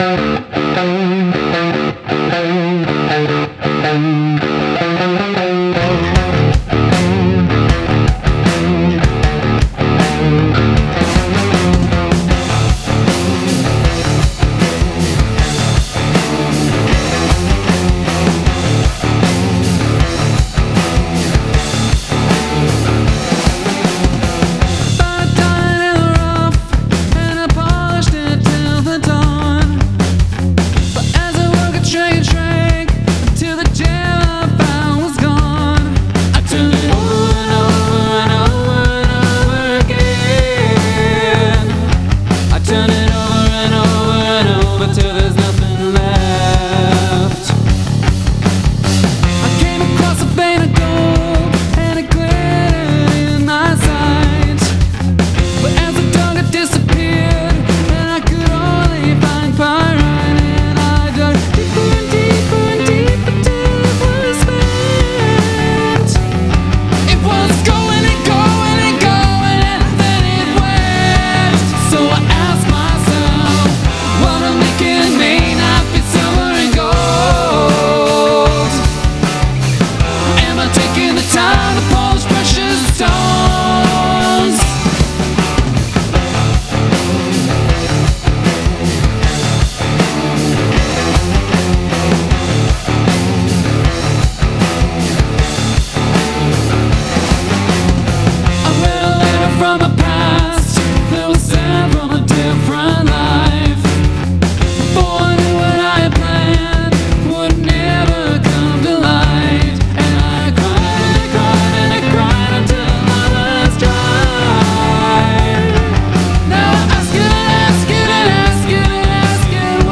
Gradual emphasis of repetitions